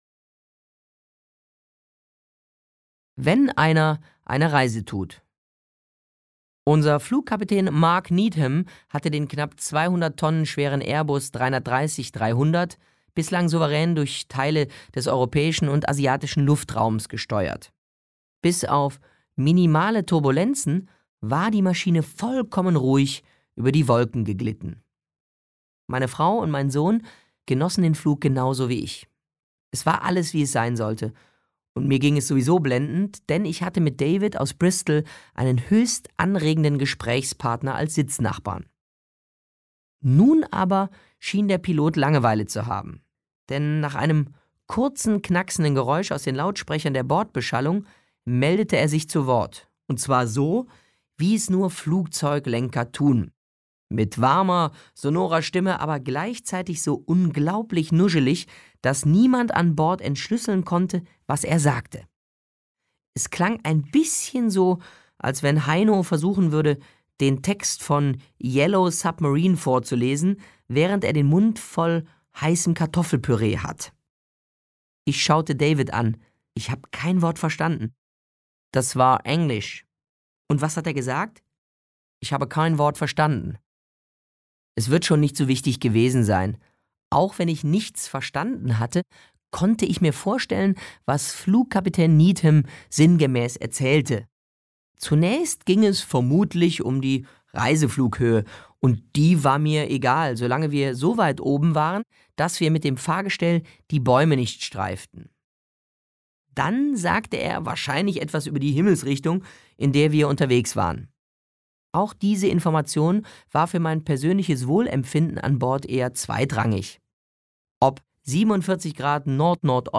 Guido Cantz (Sprecher)